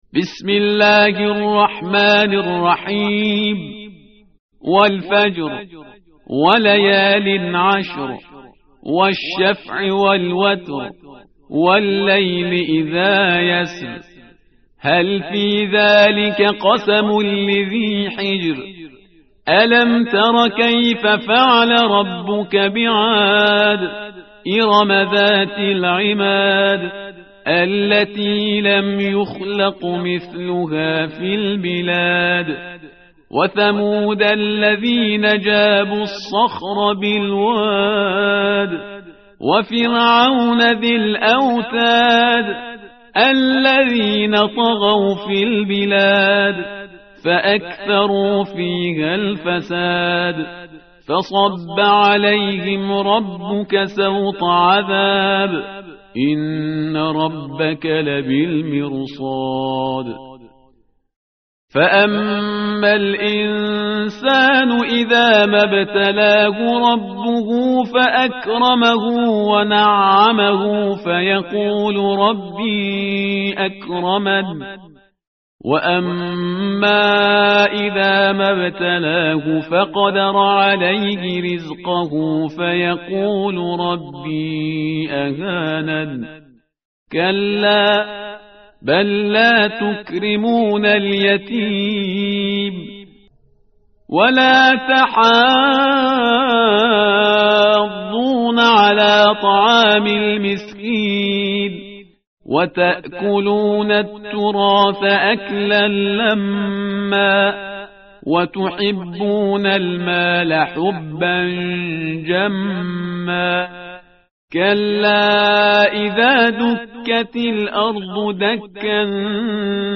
tartil_parhizgar_page_593.mp3